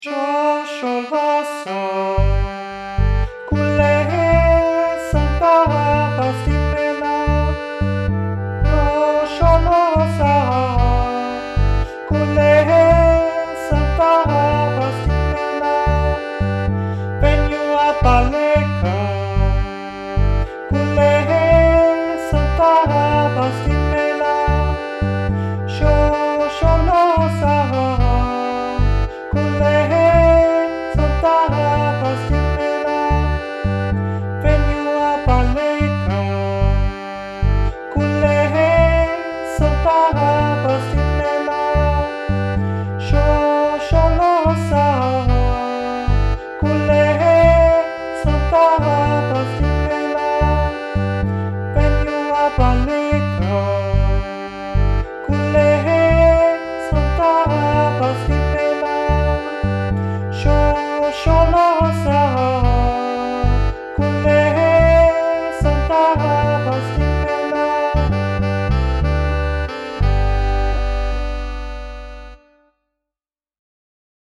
Übungsdatei 3. Stimme
3_shosholoza_tenor.mp3